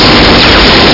Amiga 8-bit Sampled Voice
uzi.mp3